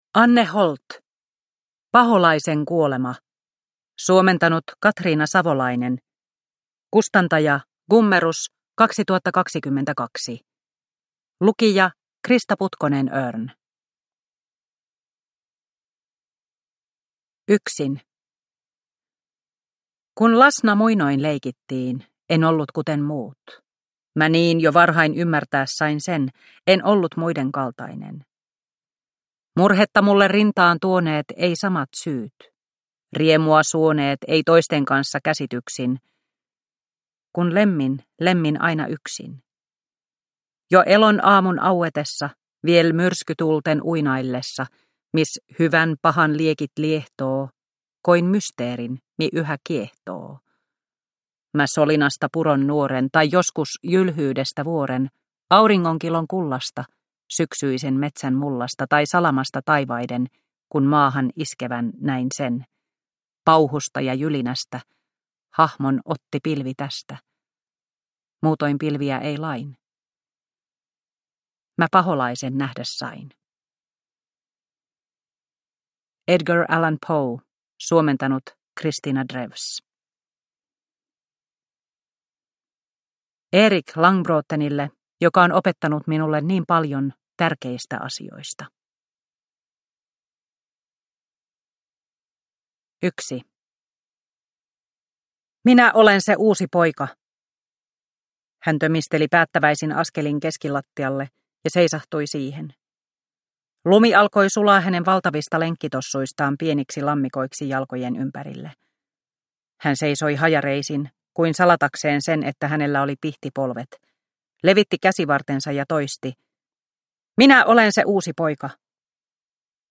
Paholaisen kuolema – Ljudbok – Laddas ner